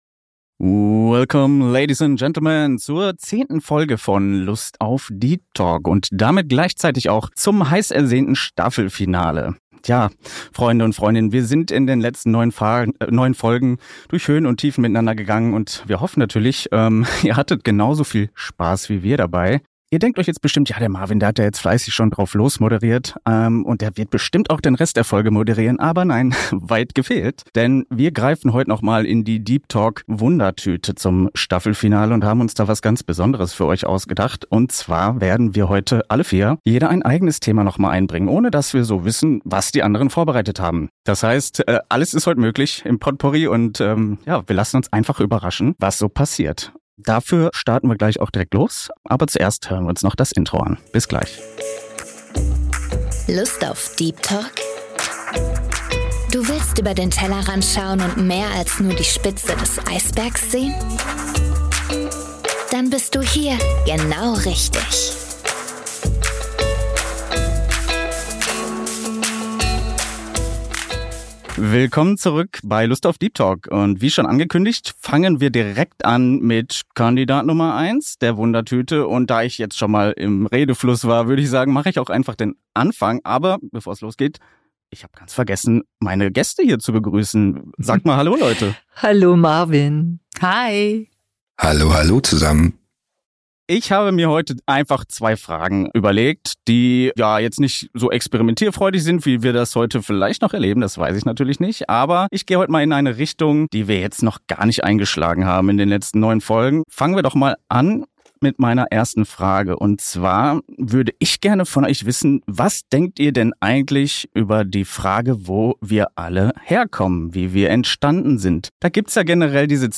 Lass dich überraschen, was sich deine vier Podcast-Freunde in ihrem vorerst letzten Gesprächsabenteuer für dich ausgedacht haben.